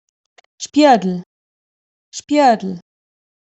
Spiedl ‘miroir’ (Ladin de Gherdëina)